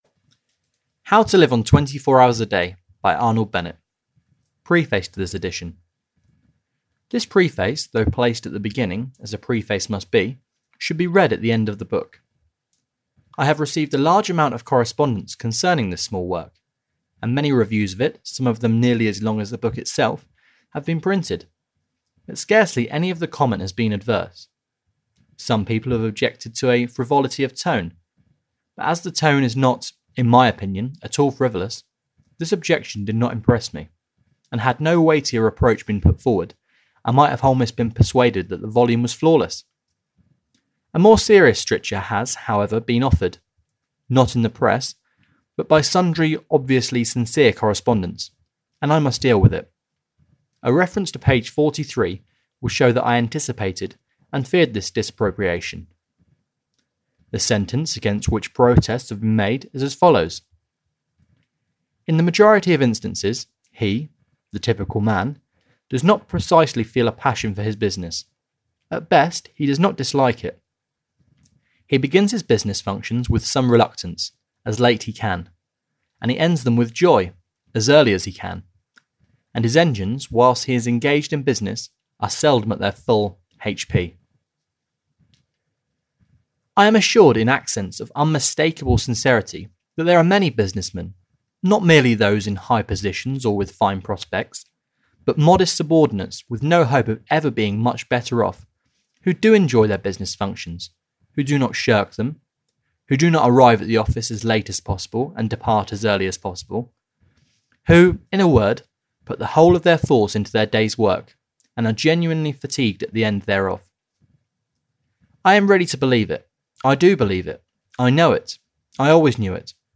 How to Live on 24 Hours a Day (EN) audiokniha
Ukázka z knihy